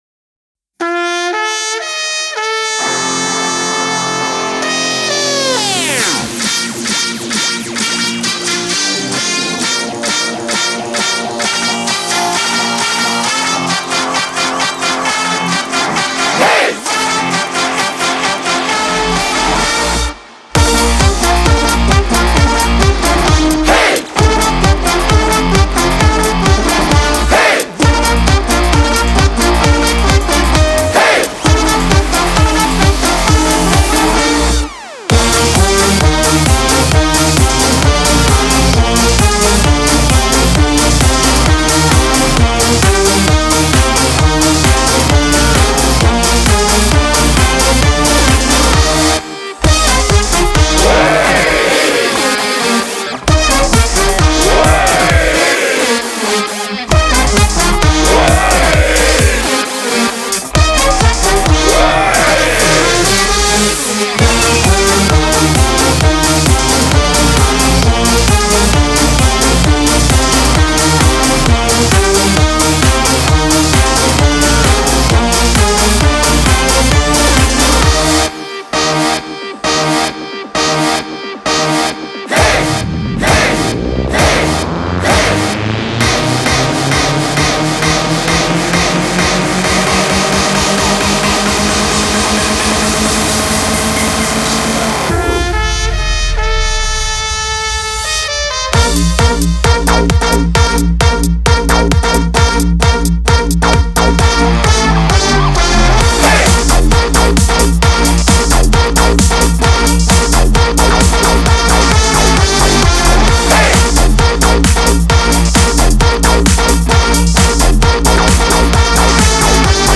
en mode électro-boum-boum